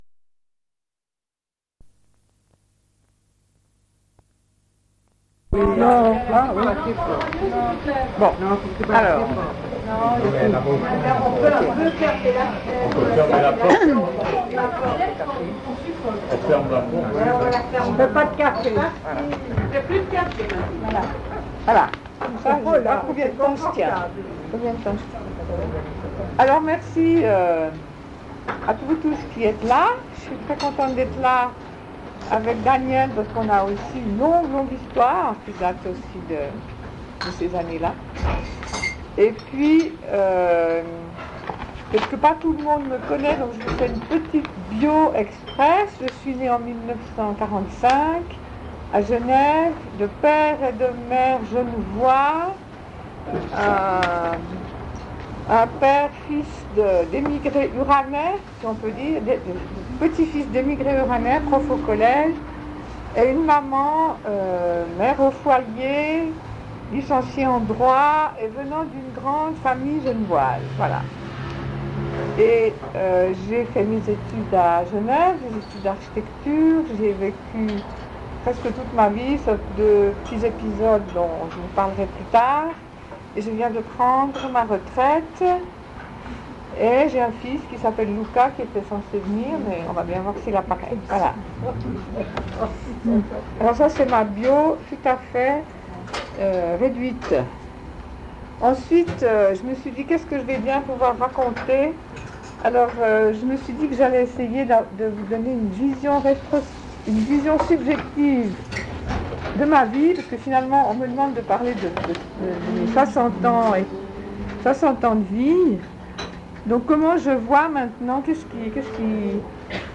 Une cassette audio
Témoignage